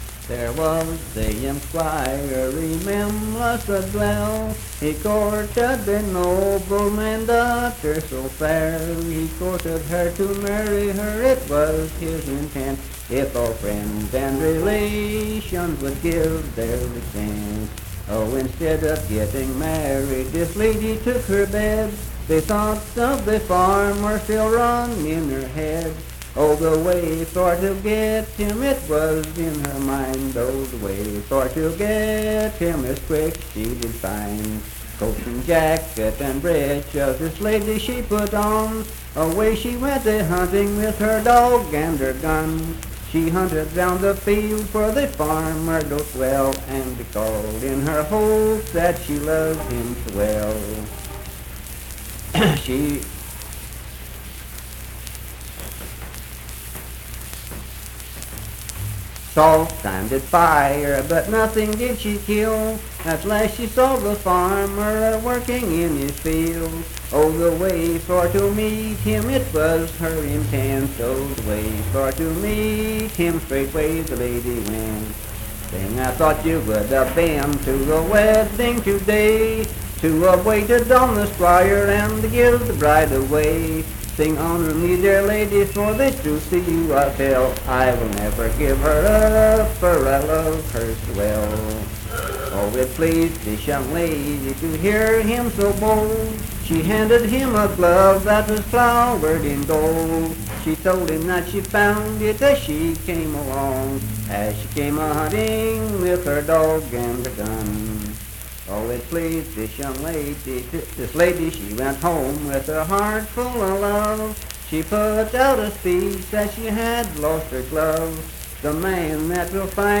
Unaccompanied vocal music performance
Verse-refrain 10 (4).
Voice (sung)